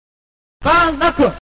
Why does TERRY BOGARD sound so damn japanese? |
fatal1gen-burnknuckle.mp3